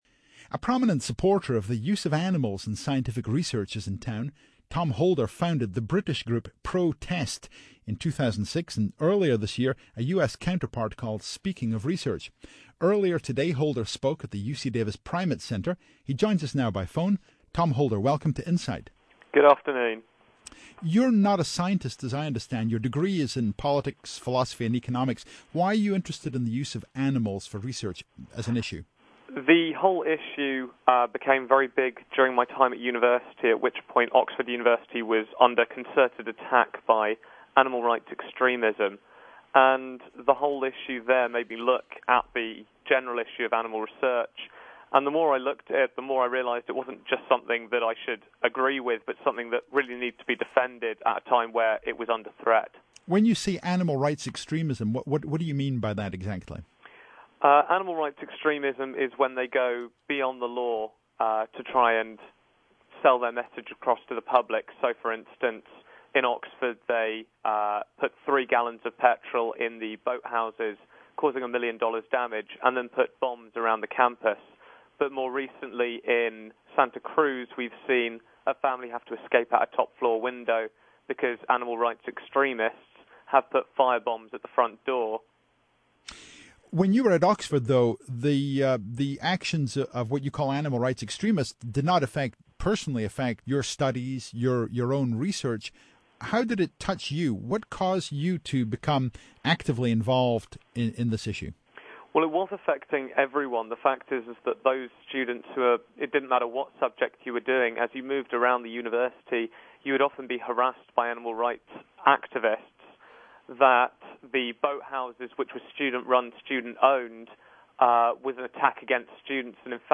insight-radio-show-august.mp3